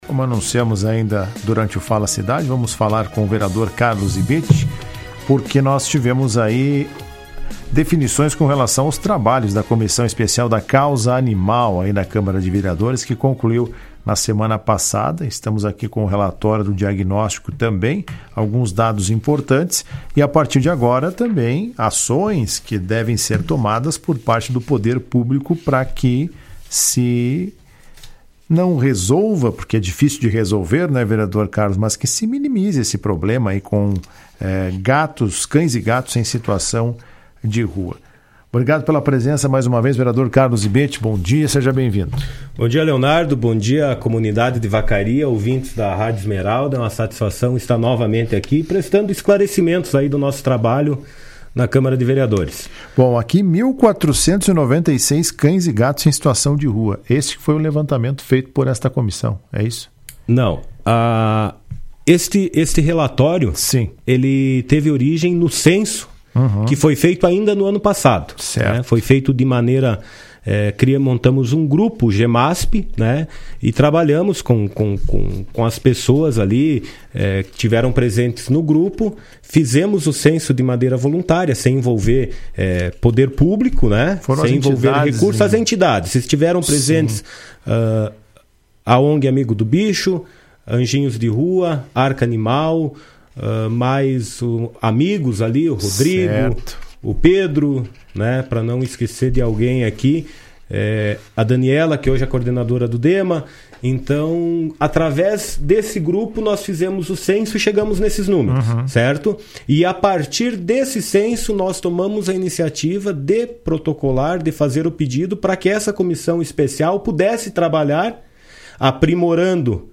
Em entrevista concedida ao programa Comando Geral da Rádio Esmeralda nesta quarta-feira (22), o vereador Carlos Zibetti (União Brasil), presidente da Comissão Especial da Causa Animal da Câmara de Vereadores, apresentou um diagnóstico preocupante sobre a realidade do município.